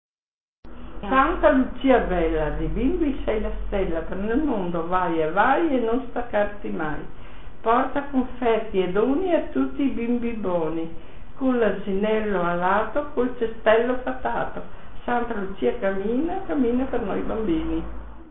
Filastrocca